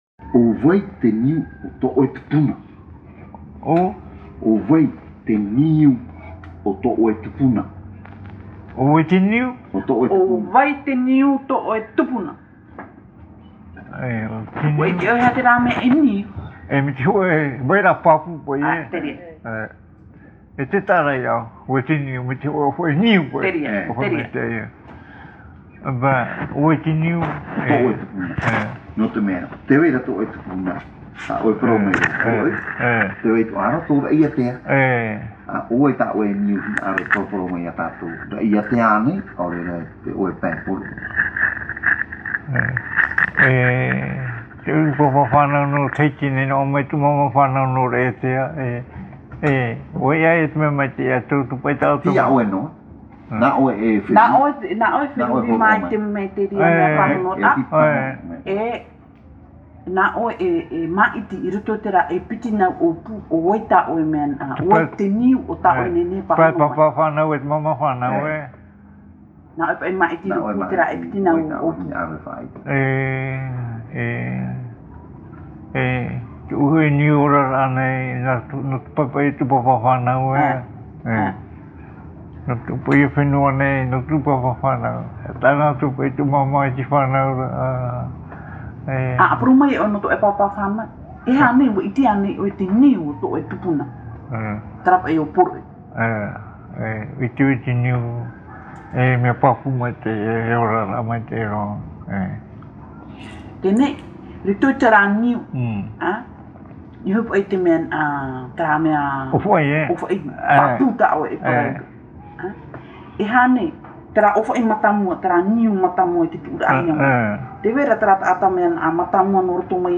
Interview réalisée à Tīpaerui sur l’île de Tahiti.
Papa mātāmua / Support original : cassette audio